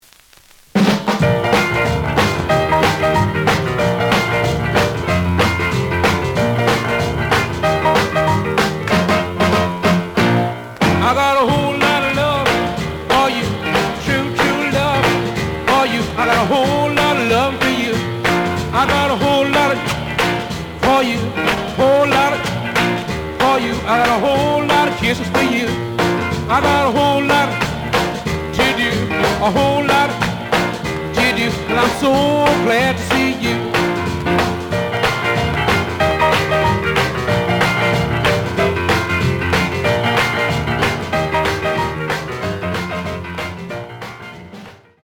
The audio sample is recorded from the actual item.
●Genre: Rhythm And Blues / Rock 'n' Roll
Some click noise on later half of A side due to scratches.